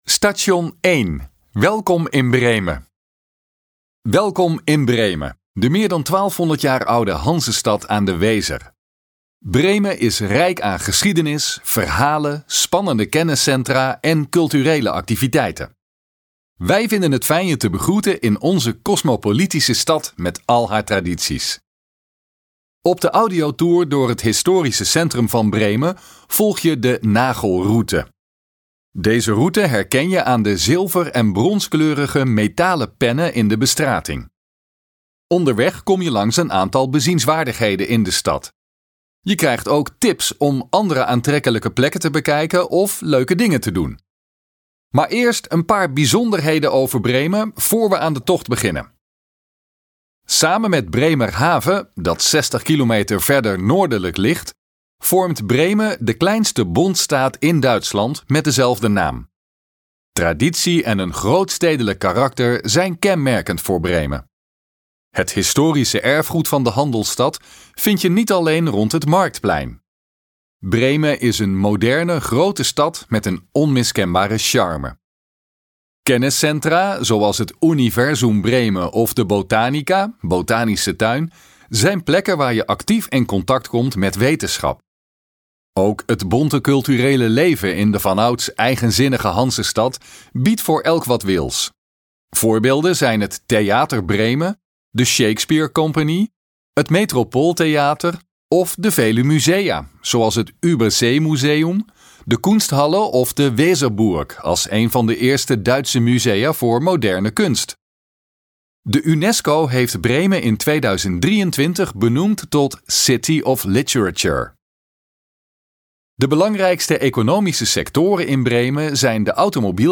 Gratis audiogids: Een wandeling door de historische binnenstad van Bremen